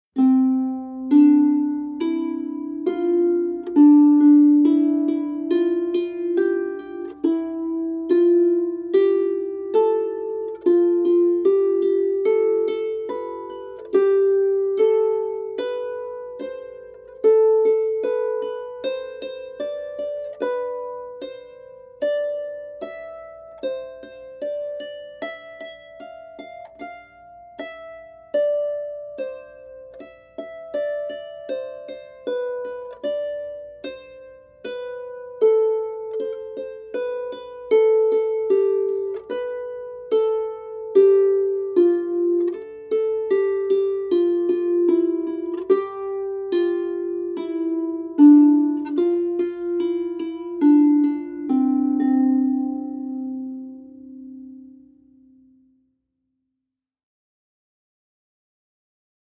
double-strung harp